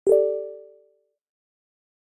levelup.ogg